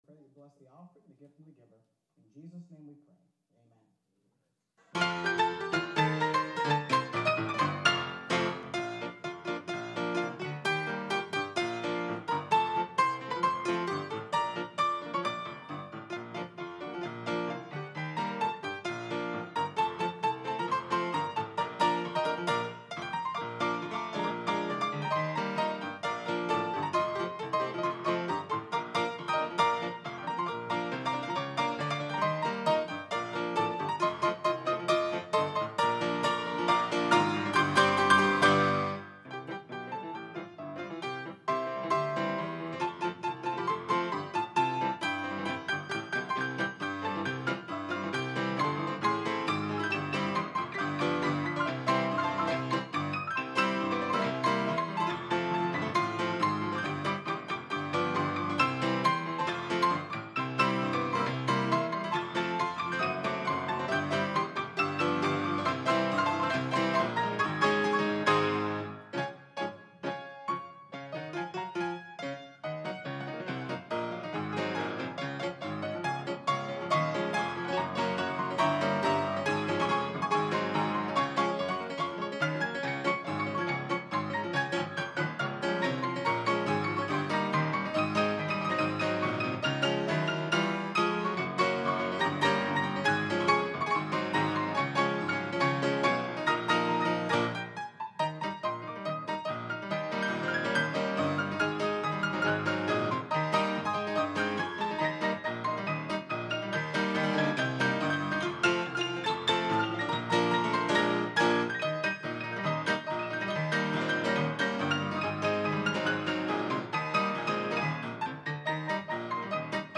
The Next Generation Youth Rally 2023 Piano Medley | Piano Duet (Two Players Simultaneously on One Piano) | Banned But Not Bound
by Piano Duet | Verity Baptist Church
Piano-Medley-Youth-Rally-Offertory-Special-Piano-Duet-Two-Players-Simultaneously-on-One-Piano.mp3